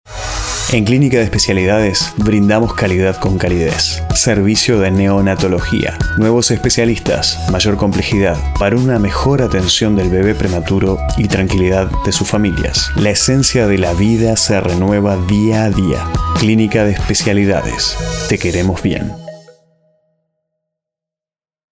Mic AT 2500 USB Edit SoundForge Pro 11 Adobe Audition 6
spanisch Südamerika
Sprechprobe: Industrie (Muttersprache):